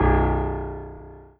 piano-ff-03.wav